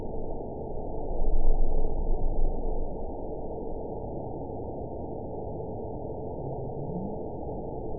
event 910475 date 01/21/22 time 22:35:38 GMT (3 years, 4 months ago) score 9.61 location TSS-AB02 detected by nrw target species NRW annotations +NRW Spectrogram: Frequency (kHz) vs. Time (s) audio not available .wav